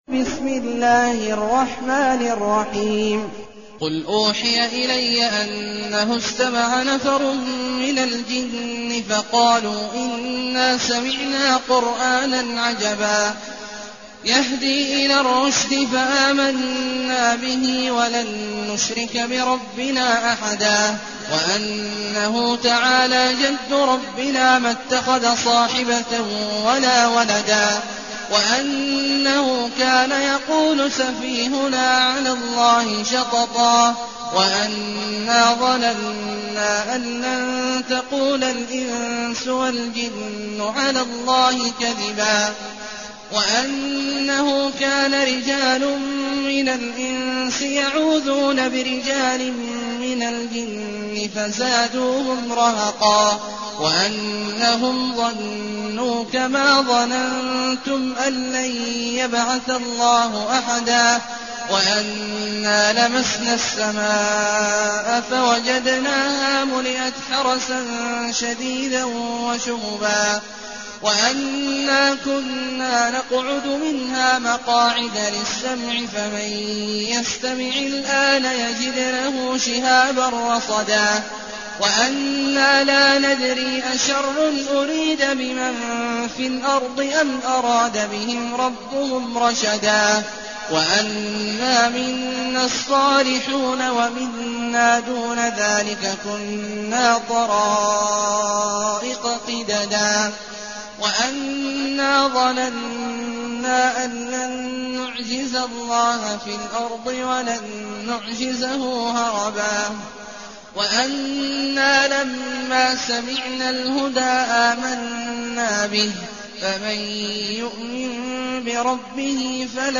المكان: المسجد النبوي الشيخ: فضيلة الشيخ عبدالله الجهني فضيلة الشيخ عبدالله الجهني الجن